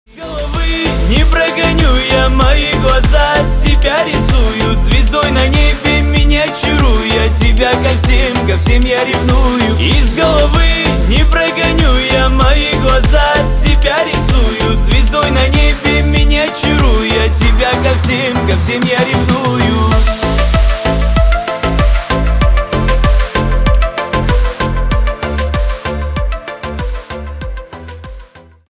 Кавказские Рингтоны
Поп Рингтоны